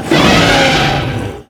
hurt4.ogg